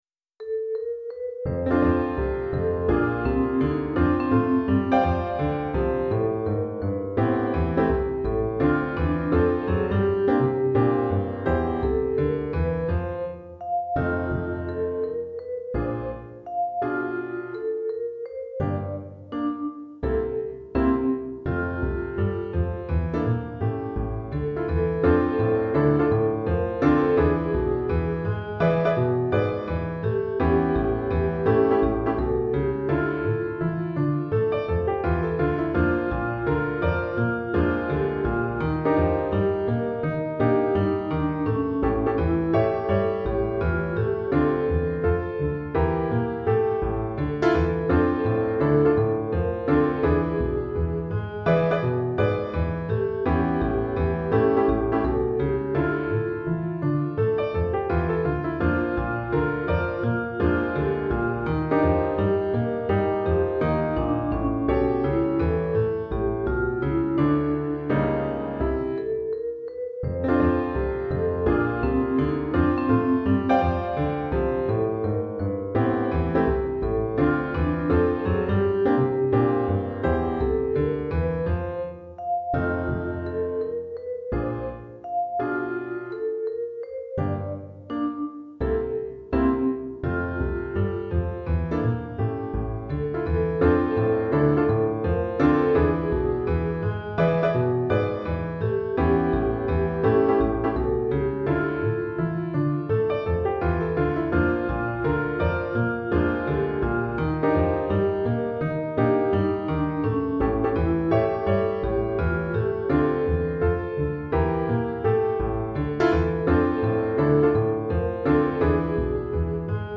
Chorale d'Enfants (8 à 11 ans) et Piano